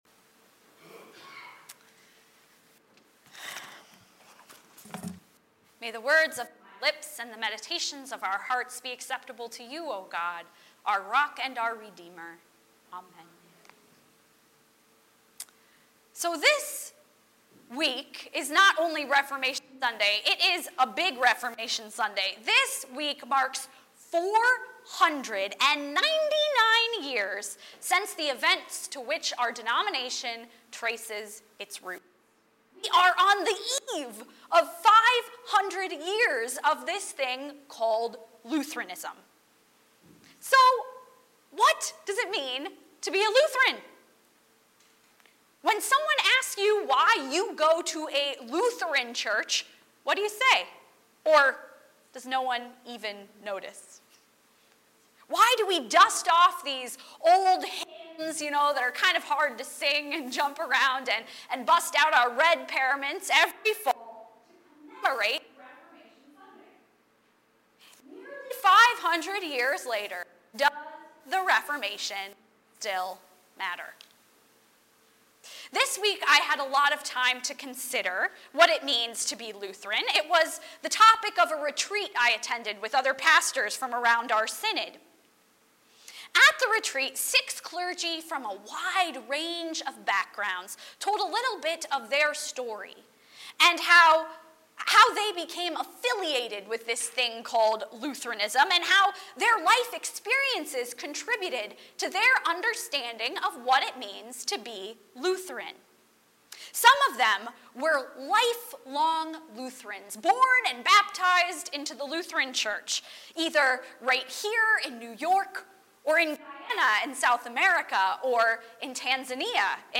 Series: Weekly Services Passage: John 8:31-36 Service Type: Sunday Morning 10:30 %todo_render% « Sola Gratia